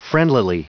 Prononciation du mot friendlily en anglais (fichier audio)
Prononciation du mot : friendlily